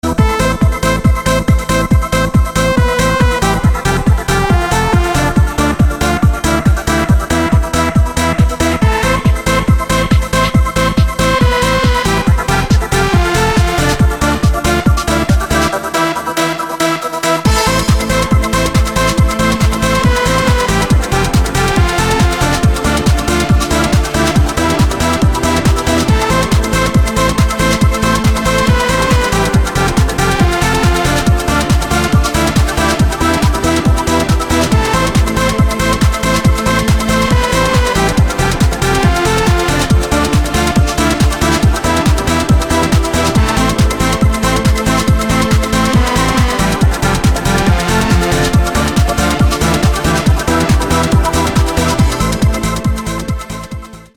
• Качество: 256, Stereo
громкие
dance
Electronic
без слов
Trance